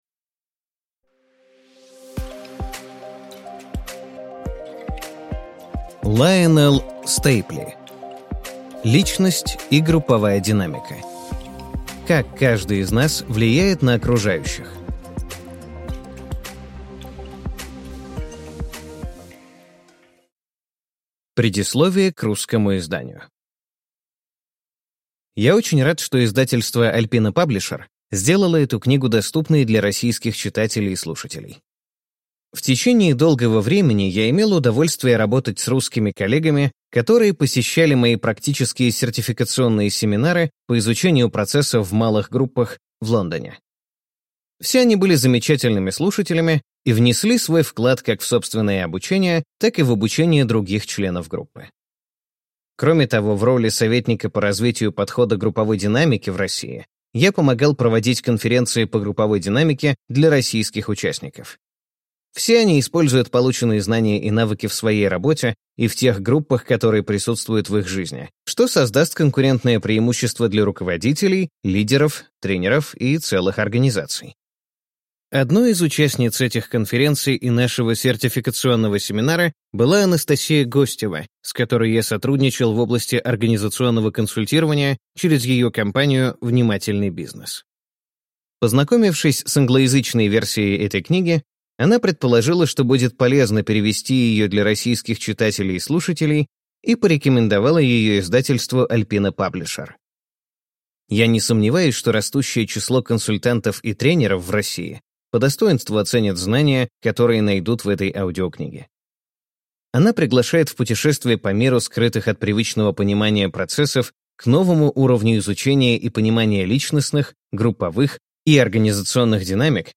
Аудиокнига Личность и групповая динамика: Как каждый из нас влияет на окружающих | Библиотека аудиокниг